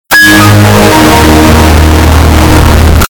Play Soothing Windows Xp Sounds - SoundBoardGuy
Play, download and share soothing windows xp sounds original sound button!!!!
soothing-windows-xp-sounds.mp3